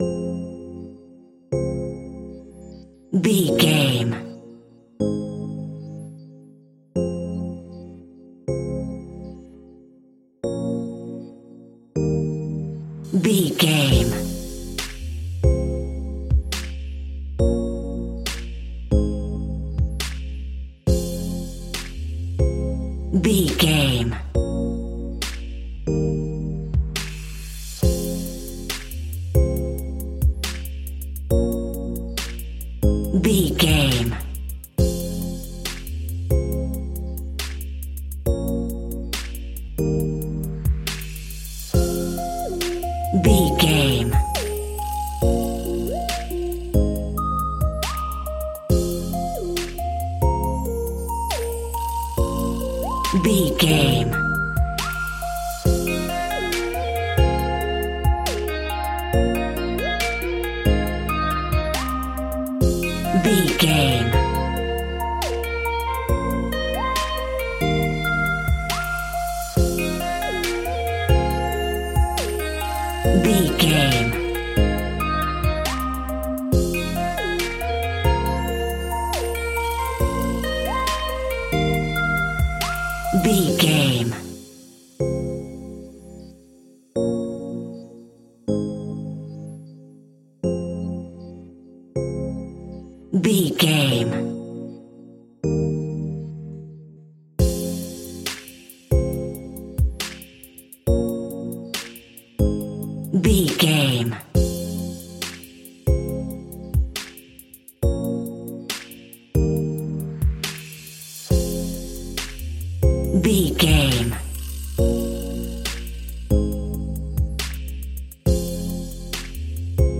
Aeolian/Minor
Slow
hip hop
chilled
laid back
Deep
hip hop drums
hip hop synths
piano
hip hop pads